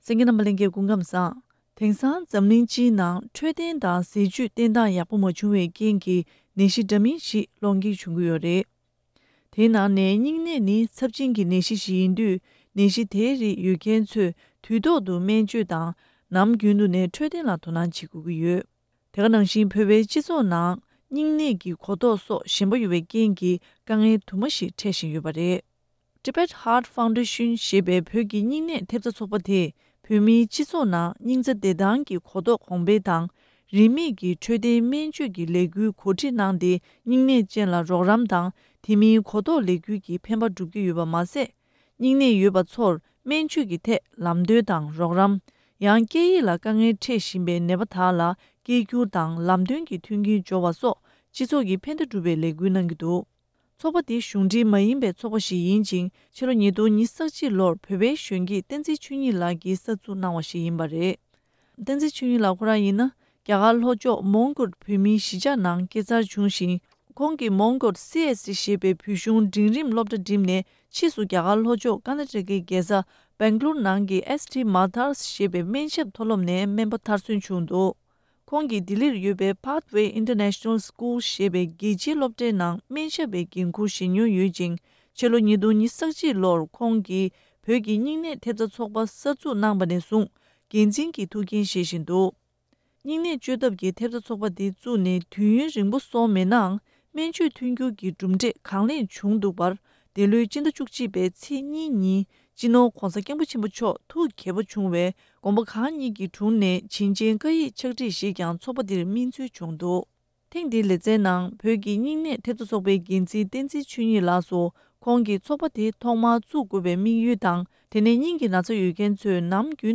ཐེངས་འདིའི་བཅར་འདྲིའི་ལེ་ཚན